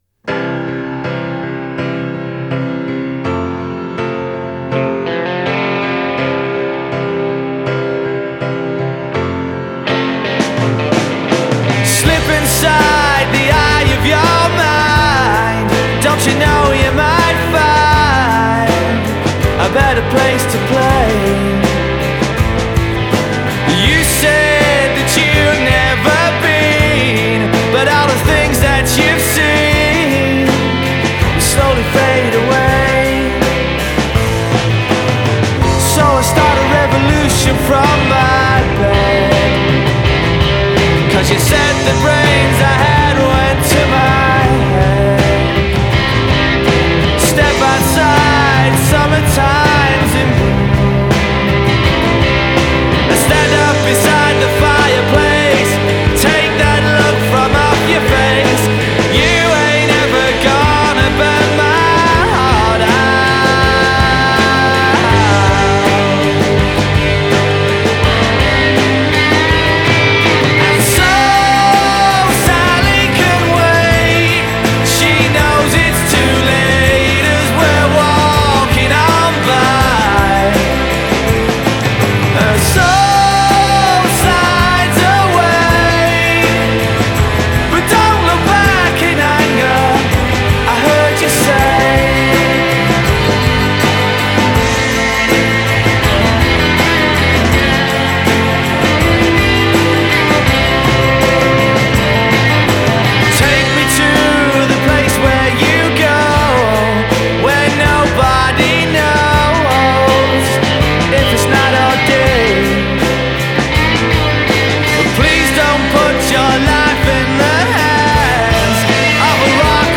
Genre: Britrock • Alternative • Indie